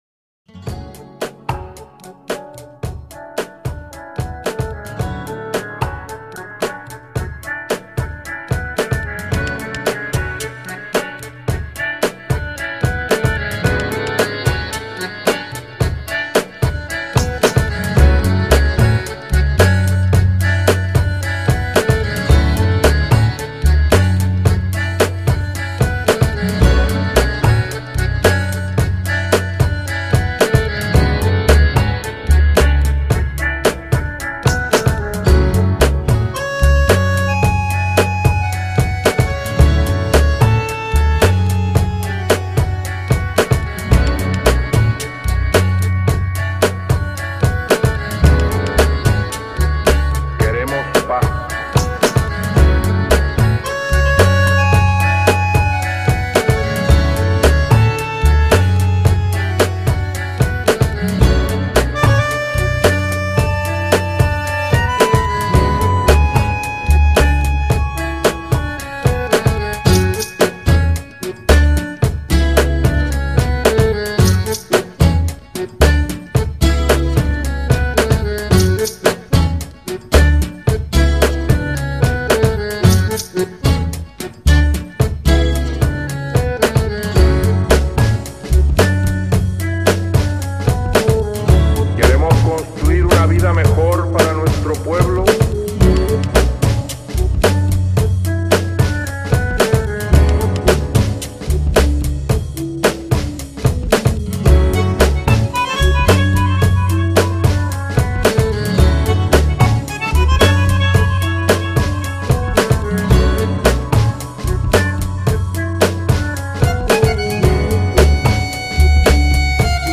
涉及分类：电子音乐 Electronica